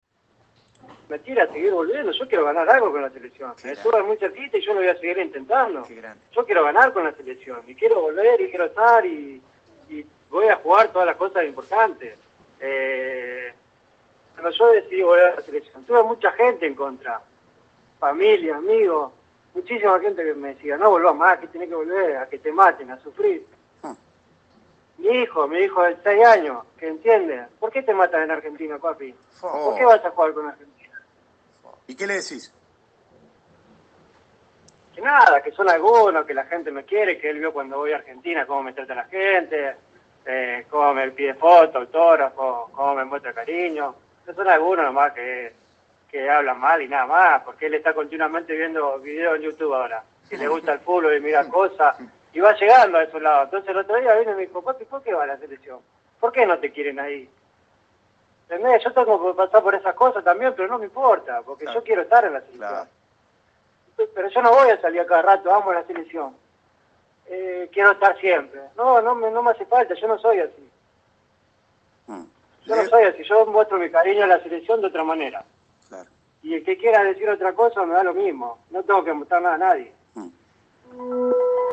Lionel Messi volvió a tomar contacto con la prensa y habló de todo.
Ahora se soltó en el programa El que abandona no tiene premio (Radio FM 947).